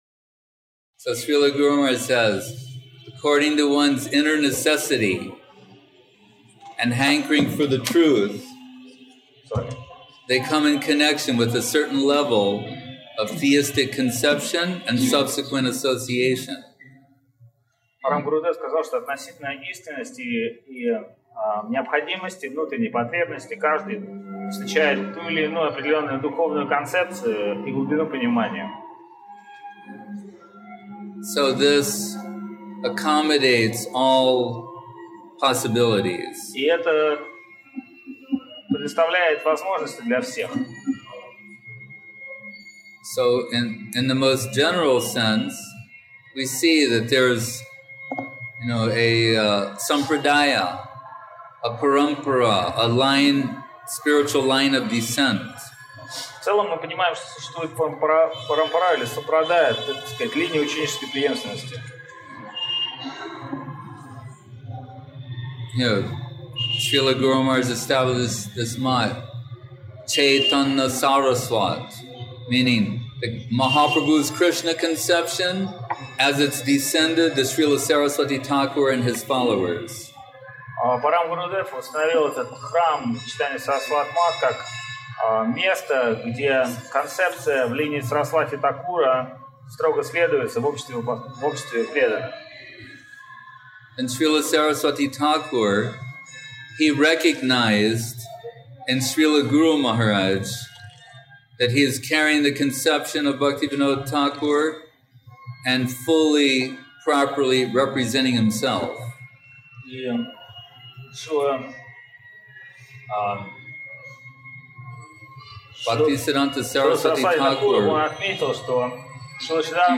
Место: ШЧСМатх Навадвип